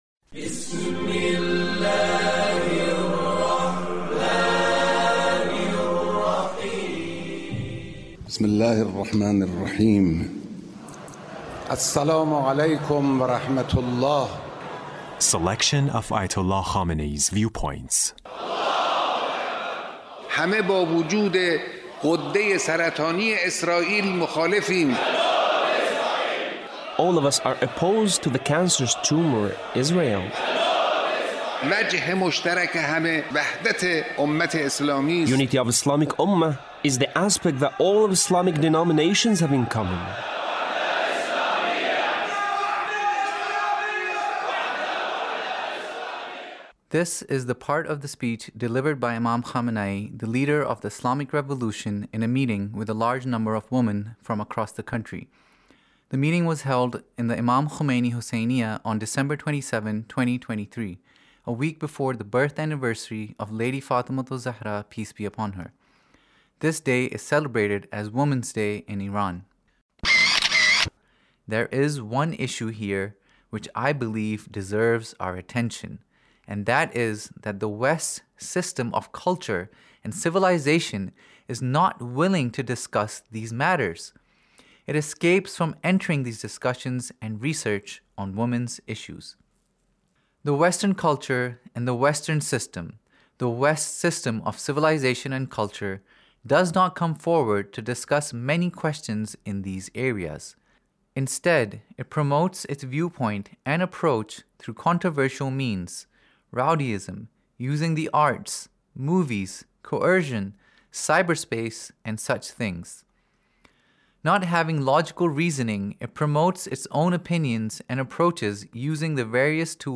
Leader's Speech about , in a meeting with Ladies